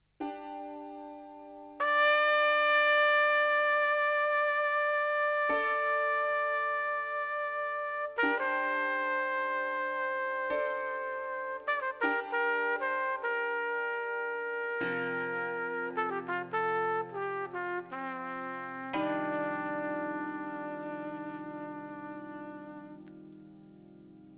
tenor és szoprán szaxofon, furulya, cimbalom, ének
trombita, hegedű
nagybőgő
dob, ütőhangszerek)hu